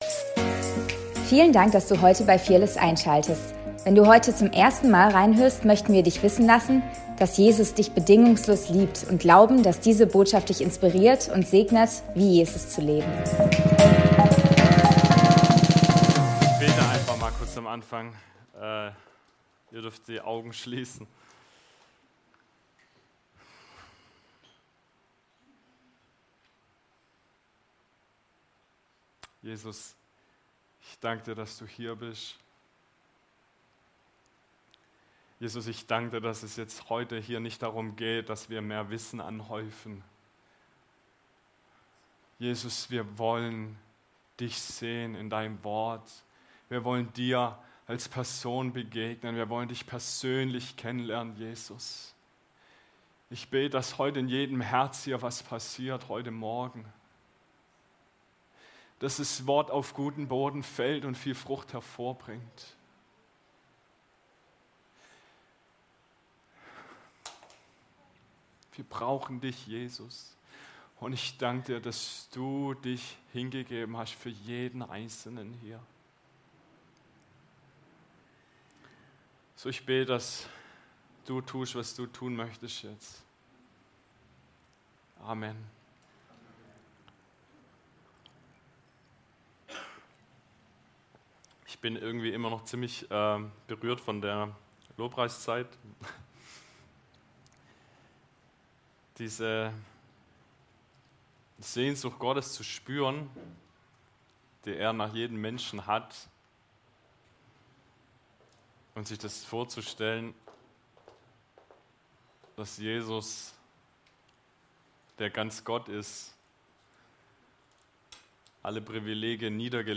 Fearless Church - Predigt vom 18.08.2024